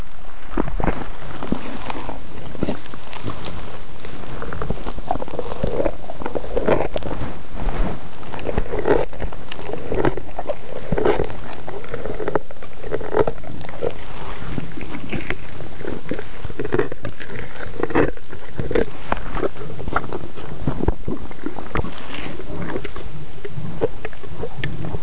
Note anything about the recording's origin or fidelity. Through a collaborative effort between computer scientists, engineers, and zoologists, custom designed acoustic bio-loggers were fitted to eight lions and recorded audio simultaneously with accelerometer and magnetometer data.